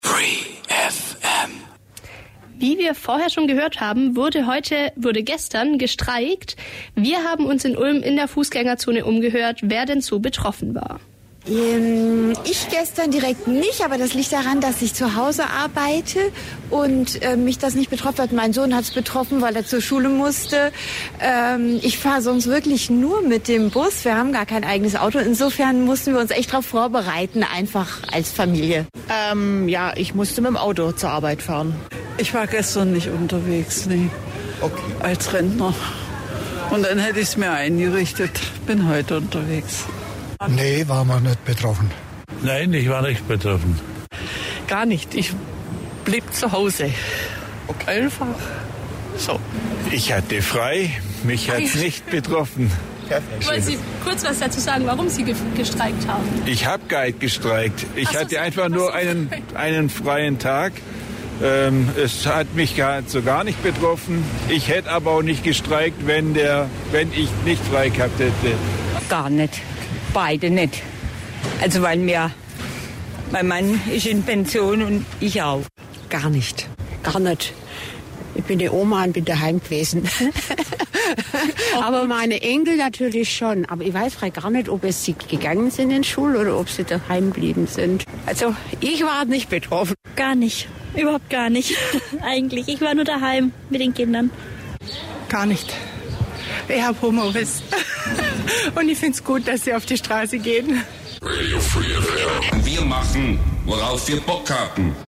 Auch die Busse in Ulm haben gestreikt. Wir haben uns in Ulm umgehört, inwiefern die Leute von den Streiks betroffen waren.